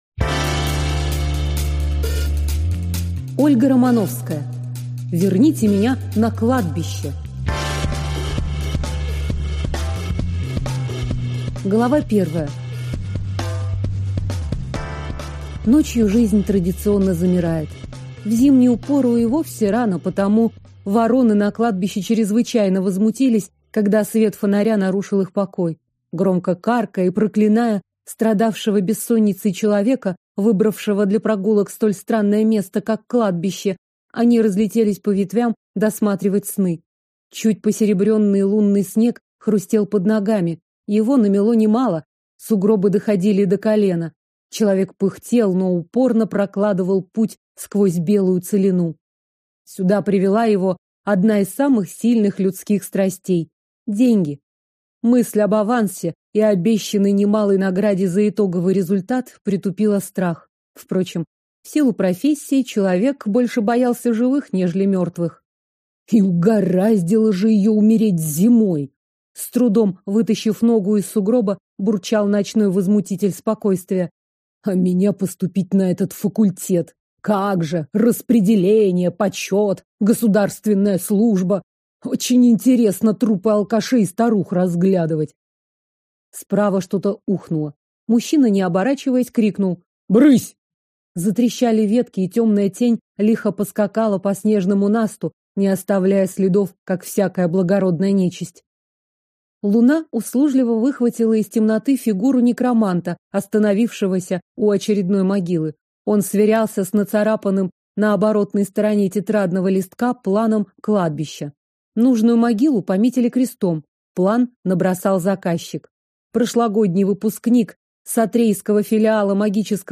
Аудиокнига Верните меня на кладбище - купить, скачать и слушать онлайн | КнигоПоиск